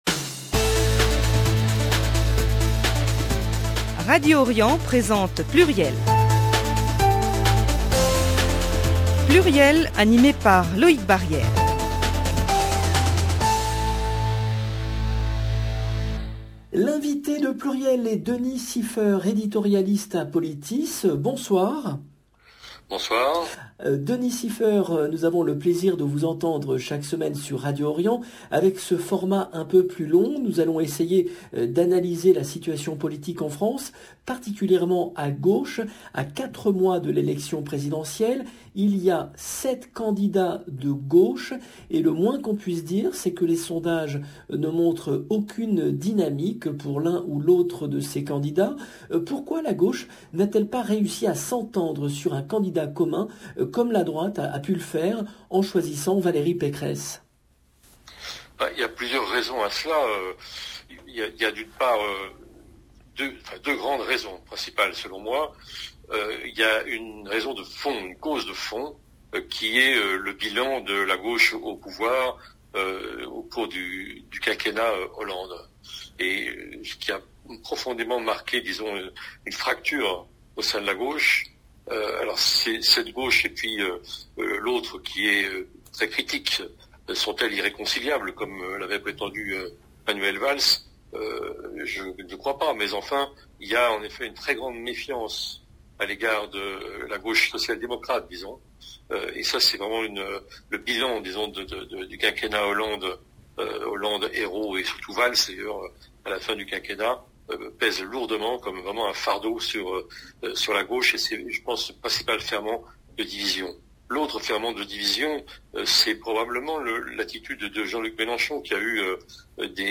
Sujet de l'émision : où en est la gauche à 4 mois de l'élection présidentielle ? Emission